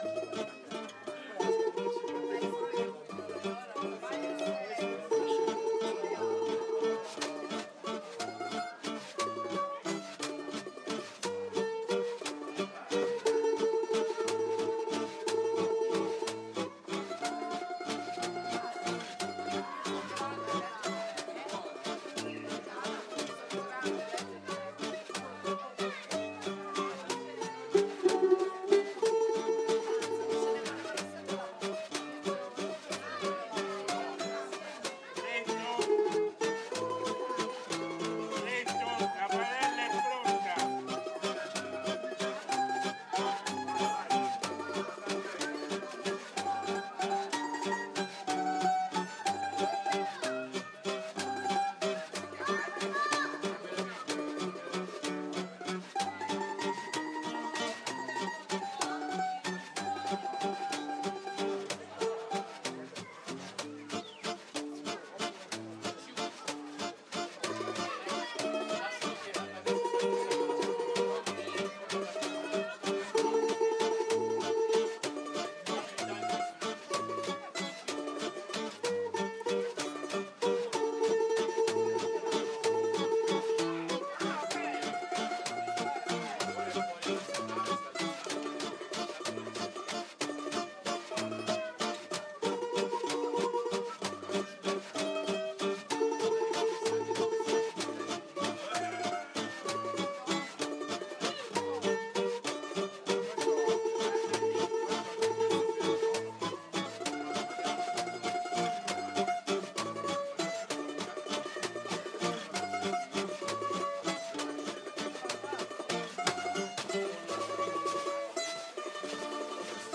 The regulars of Aristo’s bar in Barga Vecchia during the autumn months sit outside the bar in the piazza and roast chestnuts 2 or 3 times a year. It is a time for all the families to get together and join in preparing the nuts and fire, and then roasting and eating the chestnuts around the flames to the sound of music supplied by four members of The Aristodemo’s.